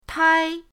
tai1.mp3